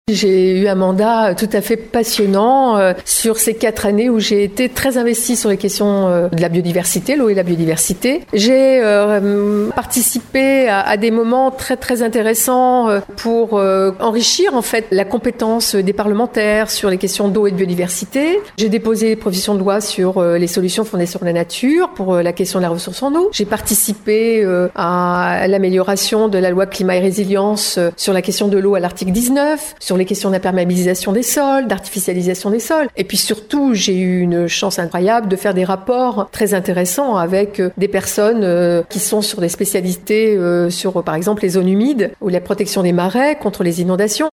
A quelques mois des élections législatives, la députée sortante de Charente-Maritime a tenu vendredi à sa permanence de Rochefort une conférence de presse de fin de mandat.